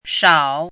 怎么读
shǎo shào
shao3.mp3